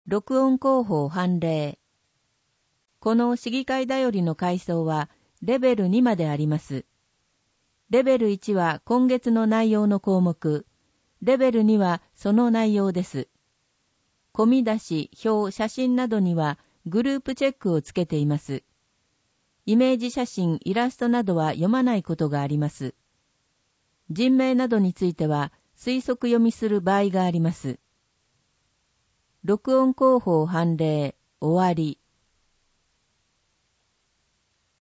声の市議会だより
なお、この音声は「音訳グループまつさか＜外部リンク＞」の皆さんの協力で作成しています。